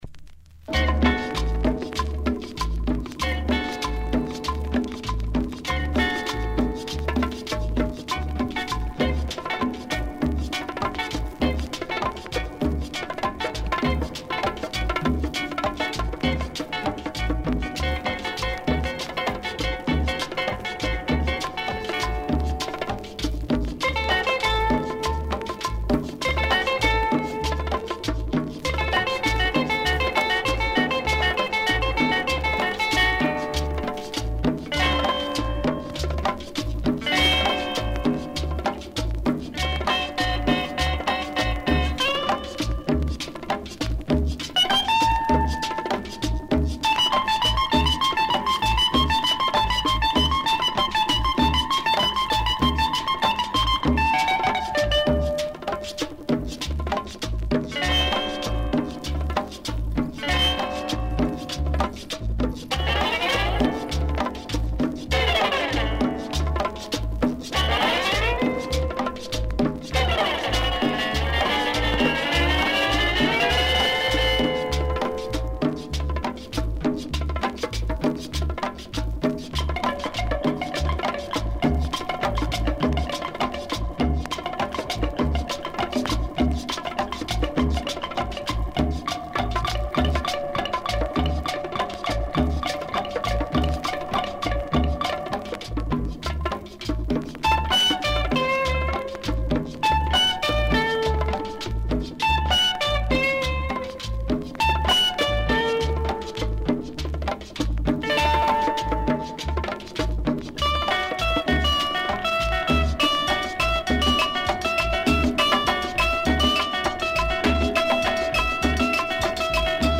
Published August 29, 2009 Cumbia Comments